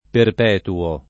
vai all'elenco alfabetico delle voci ingrandisci il carattere 100% rimpicciolisci il carattere stampa invia tramite posta elettronica codividi su Facebook perpetuare [ perpetu- # re ] v.; perpetuo [ perp $ tuo ] — pres. 1a pl. perpetuiamo [ perpetu L# mo ]